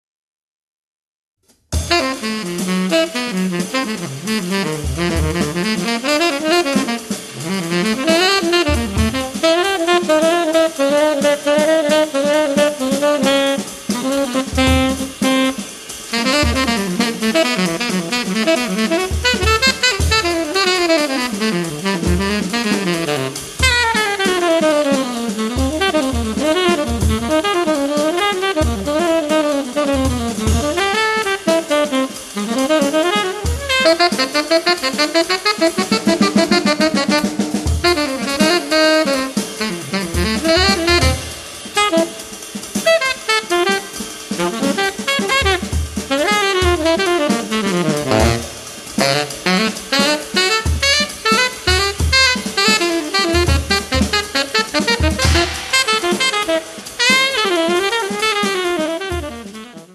sax tenore e soprano
pianoforte
contrabbasso
batteria